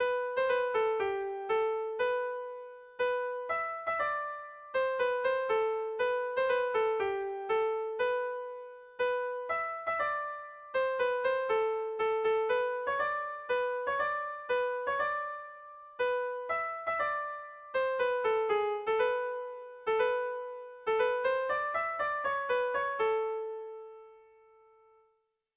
Erromantzea
7A / 7B / 10A / 8B / 10B
ABDE